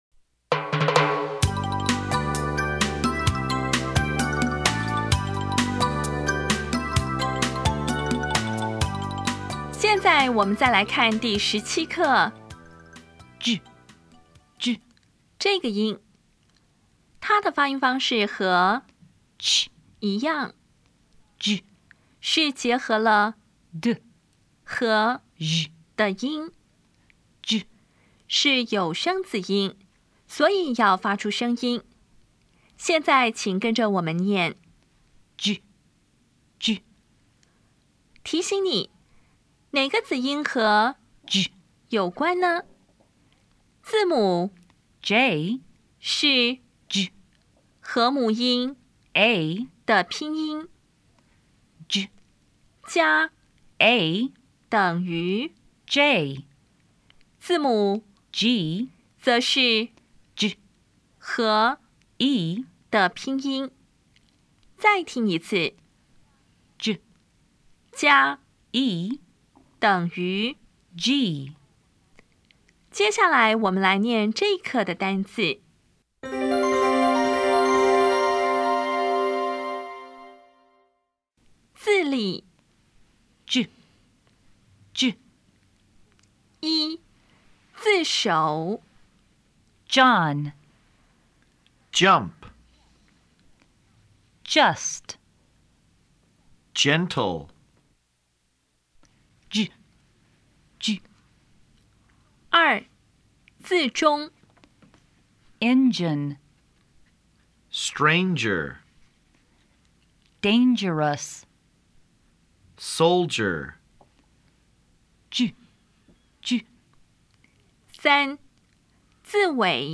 当前位置：Home 英语教材 KK 音标发音 子音部分-2: 有声子音 [dʒ]
音标讲解第十七课
比较[tʃ] [dʒ]       [tʃ](无声) [dʒ](有声)
Listening Test 8